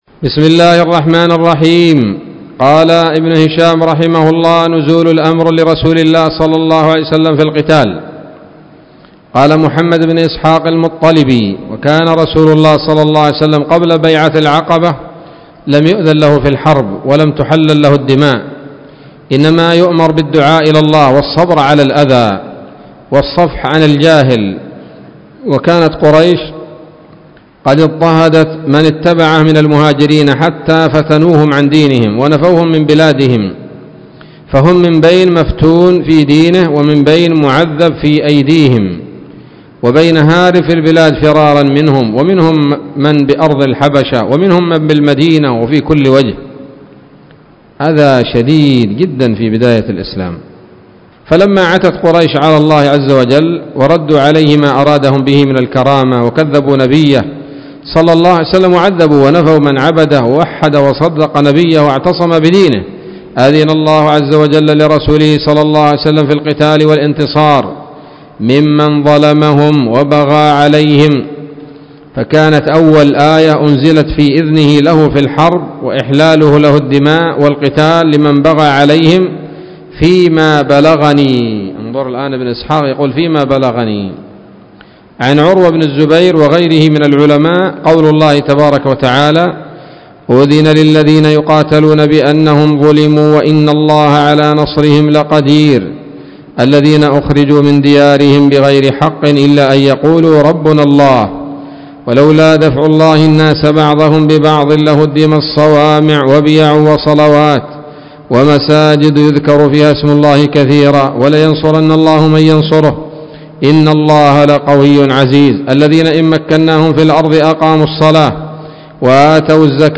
الدرس الثامن والستون من التعليق على كتاب السيرة النبوية لابن هشام